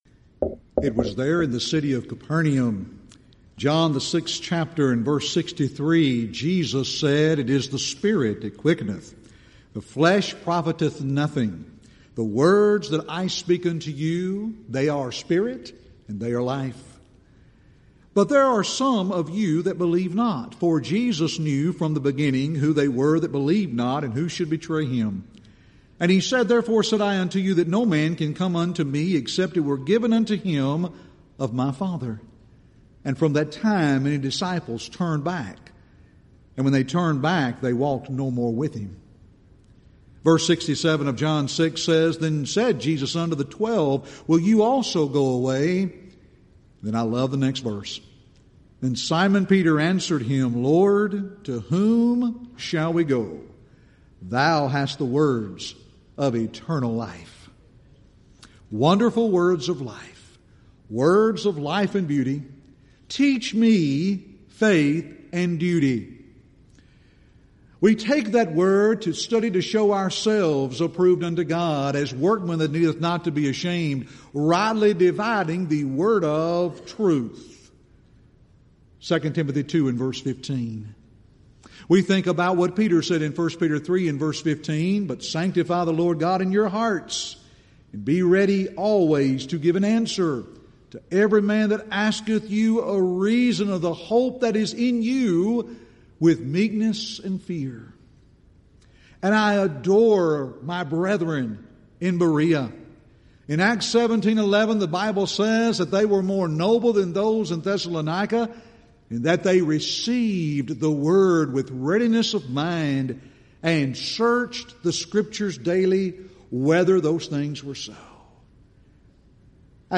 Event: 30th Annual Southwest Bible Lectures
lecture